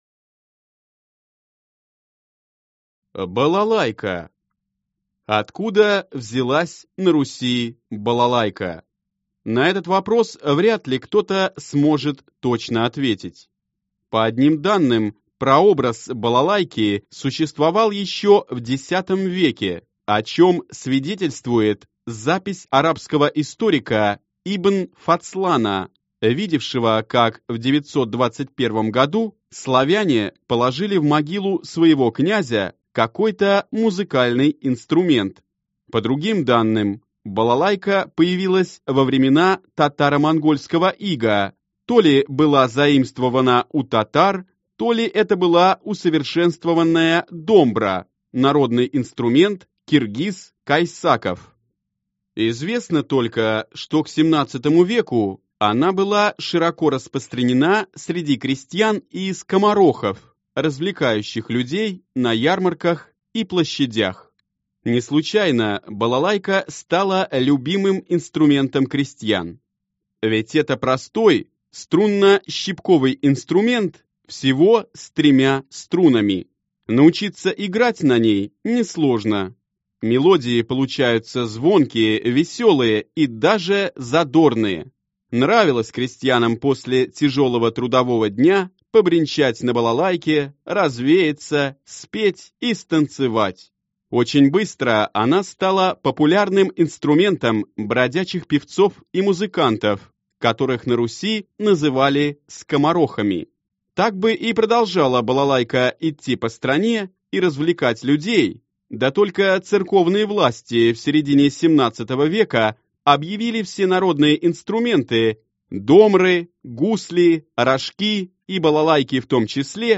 Аудиокнига Культура и традиции России | Библиотека аудиокниг